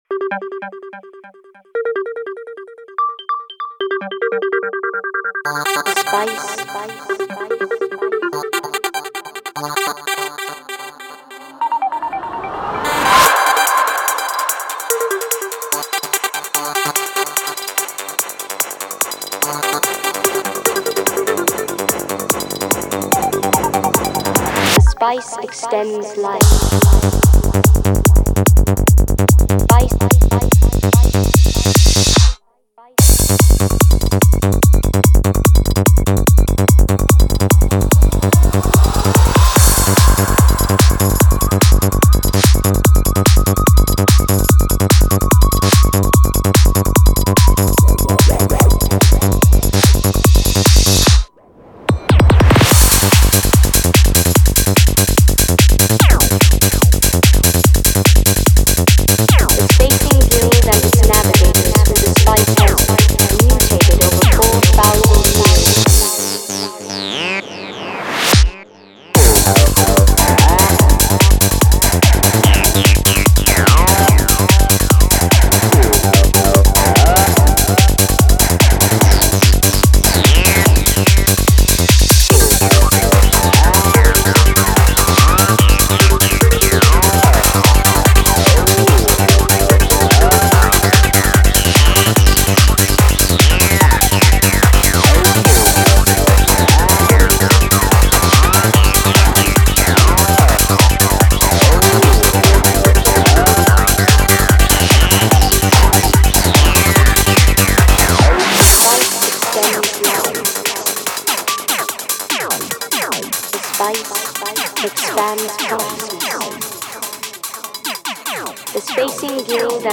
как лучшую в стиле psychedelic trance
явивший собой коллекцию эпического трэнса.